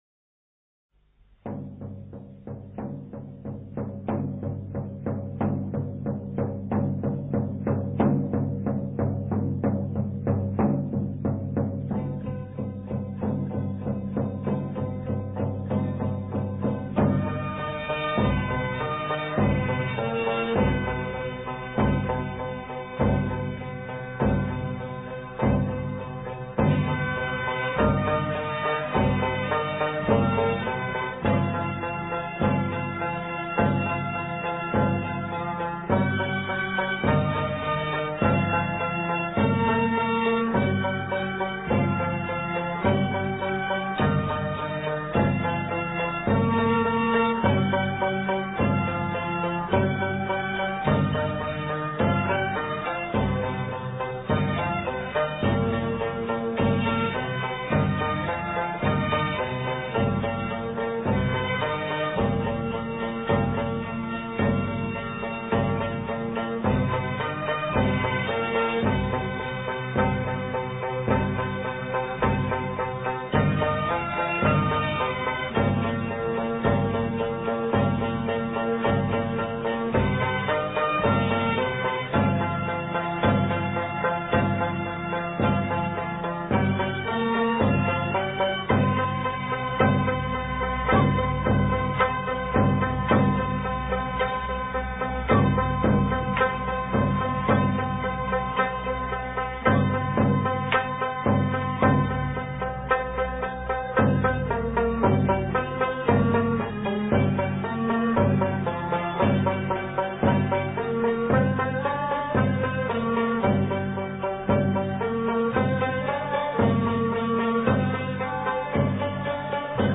鑼鼓 古箏